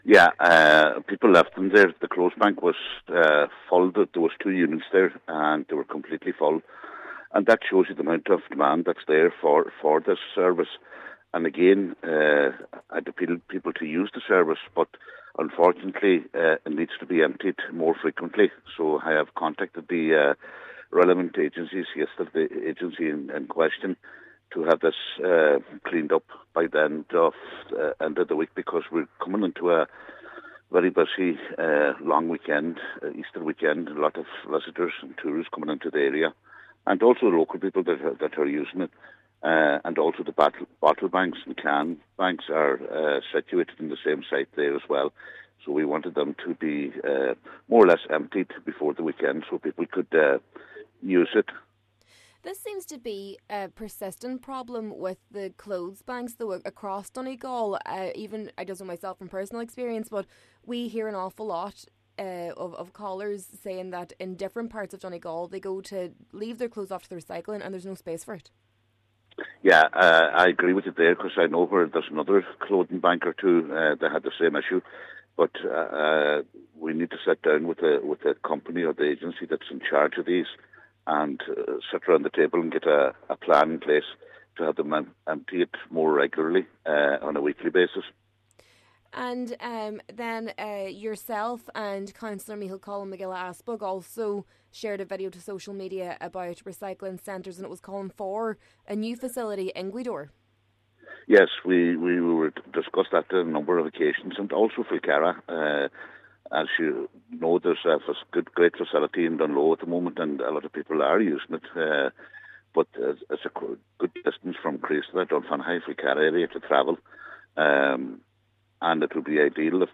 Cllr O’Fearraigh says that he hopes it will be cleared before the bank holiday weekend, as there will be a high level of footfall in the area: